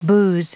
Prononciation du mot booze en anglais (fichier audio)
Prononciation du mot : booze